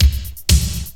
• 123 Bpm 2000s Disco Drum Groove C# Key.wav
Free drum loop - kick tuned to the C# note. Loudest frequency: 1671Hz